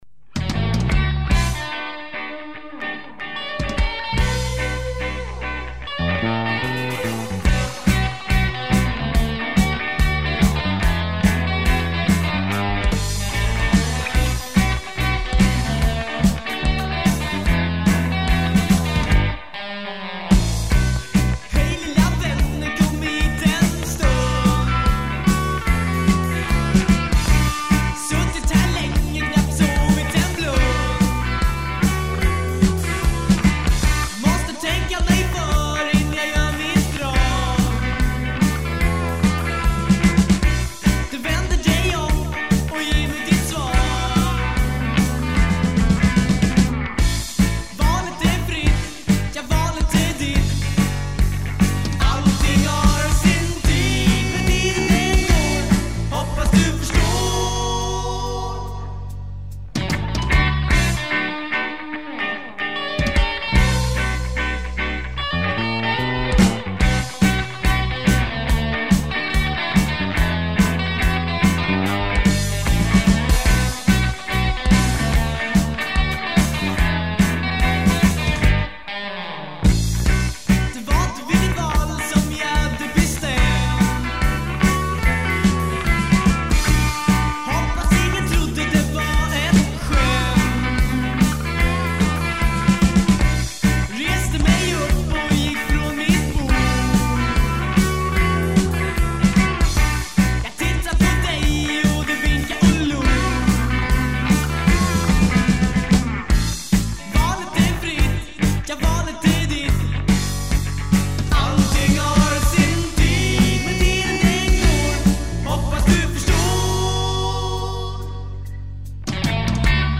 Guitar
Drums
Bass
Trumpet
Trombone
Saxophone